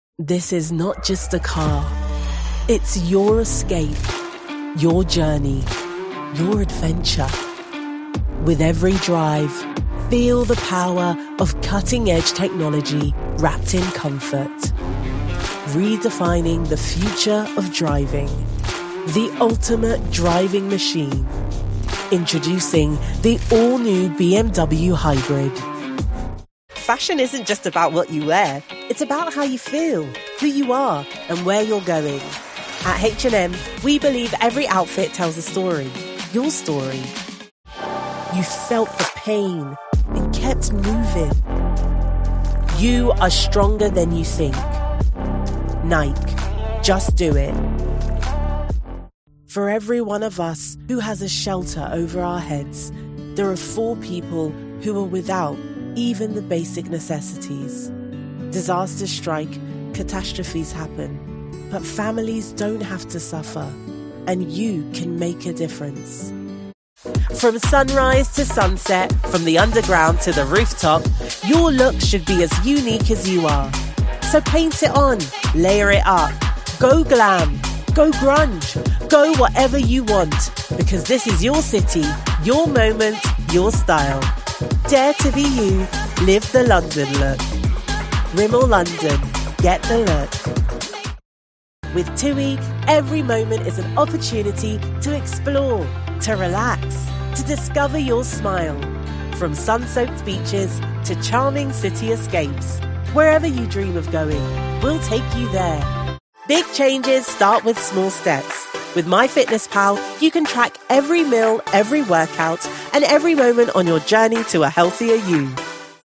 Contrata actores de voz tranquilos para tu proyecto
Inglés (Reino Unido)
Artista de doblaje y actor de doblaje británico. Acento londinense auténtico y distintivo que es divertido, fresco, amigable y...
Emocional
Creíble
Sociable